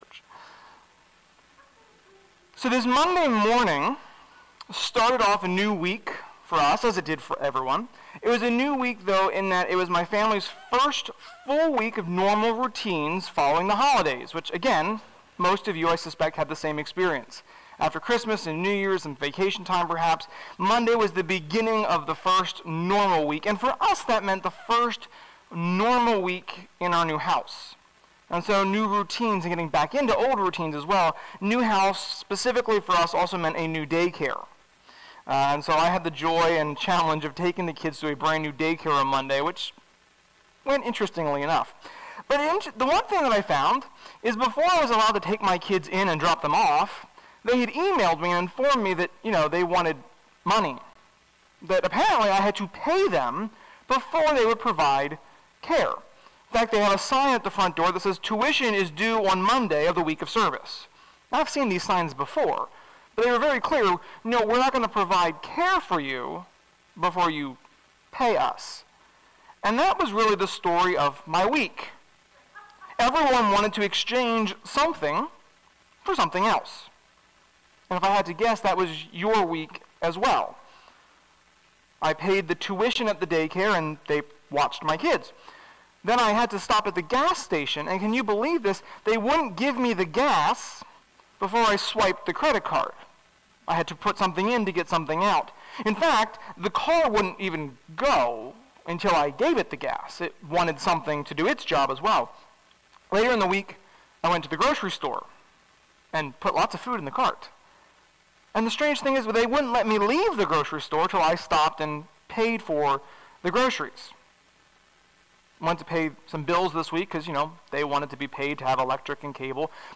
Sermon-1.10.21.mp3